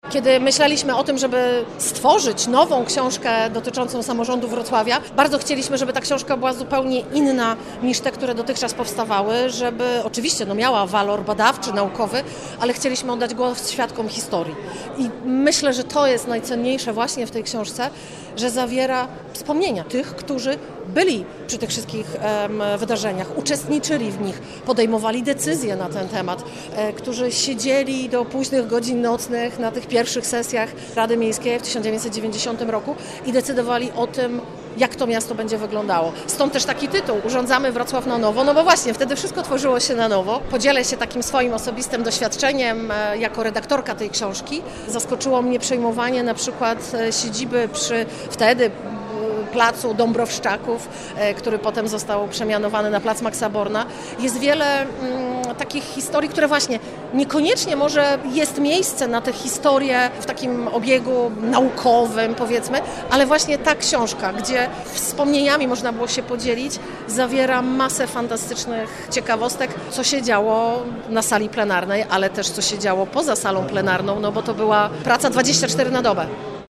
We wrocławskim Ratuszu odbyła się promocja książki pt. „Urządzamy Wrocław na nowo. Rada Miejska Wrocławia 1990-1994”.